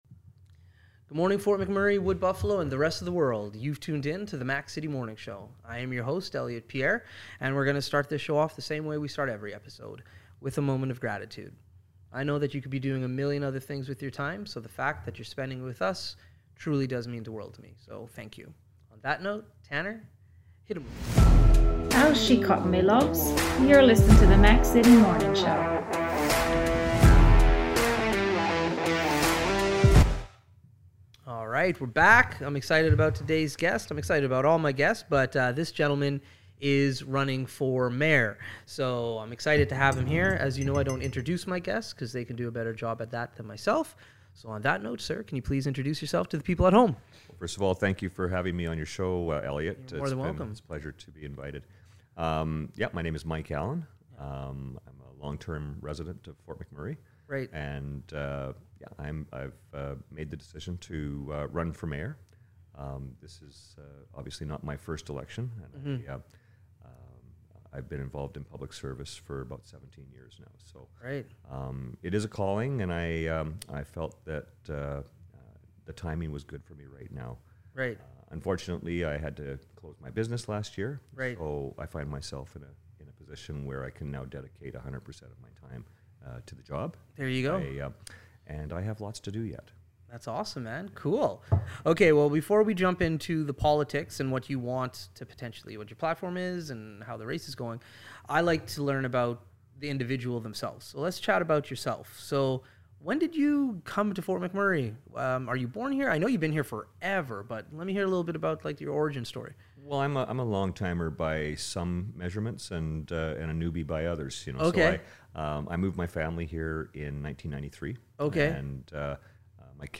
Councillor Mike Allen came by today for a chat!